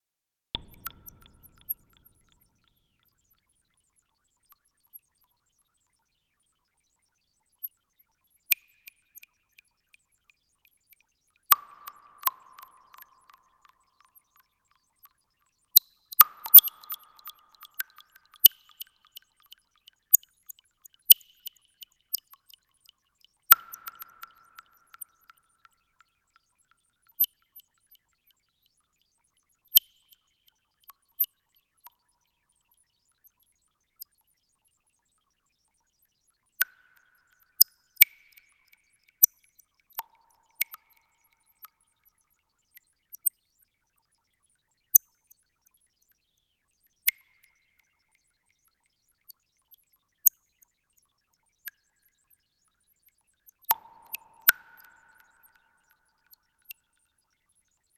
synthesis only
a bit cavernous. but dripping.
A4 single track, no sequencer used.